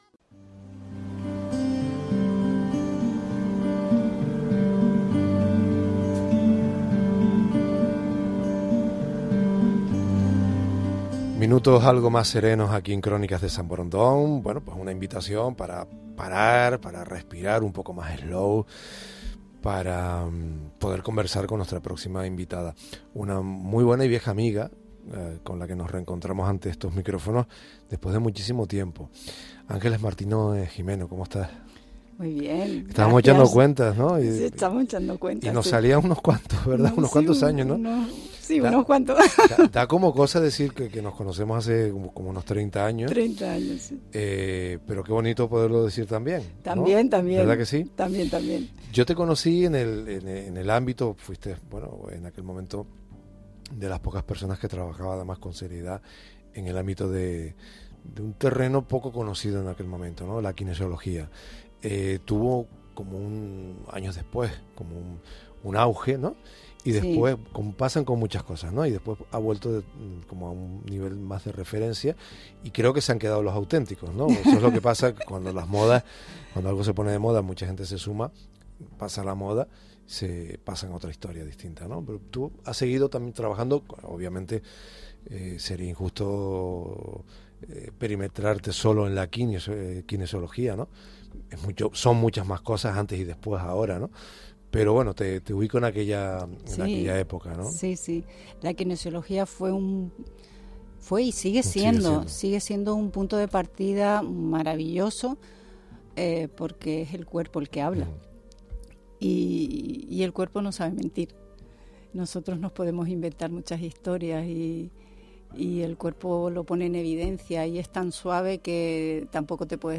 una bonita conversación